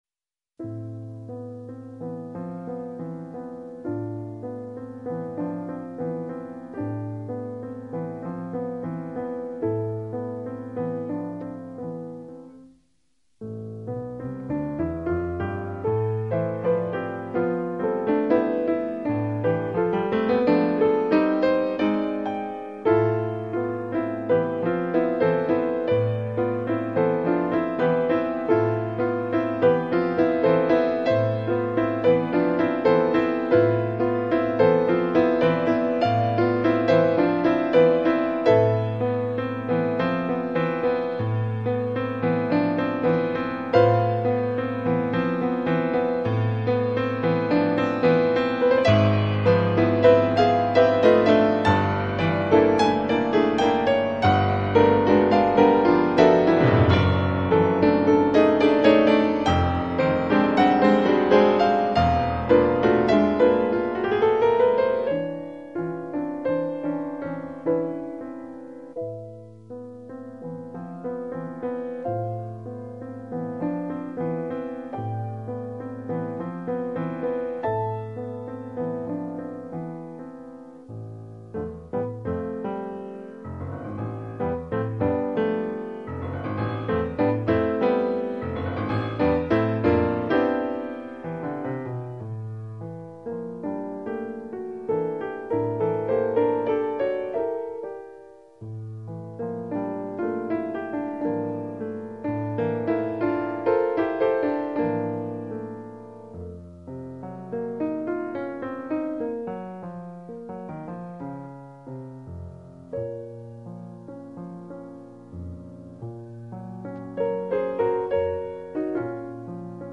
pianoforte
milonga prelude
per pianoforte